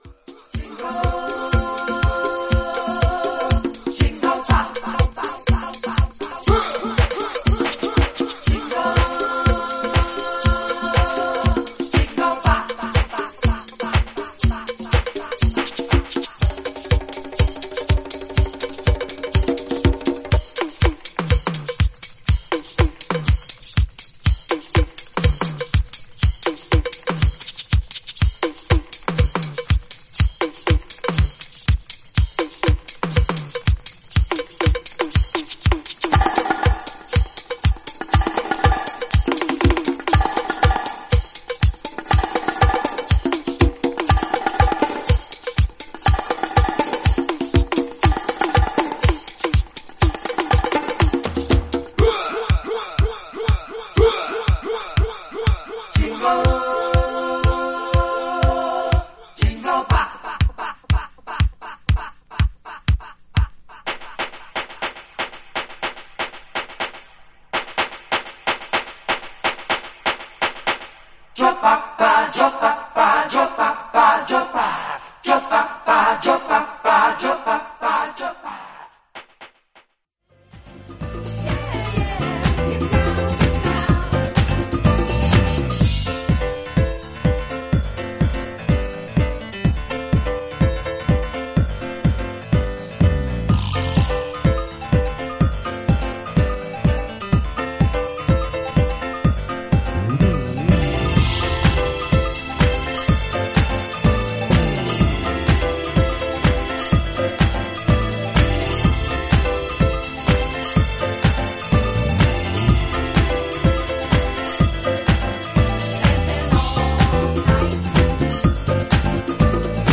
両曲共にDJでも重宝するアカペラも収録。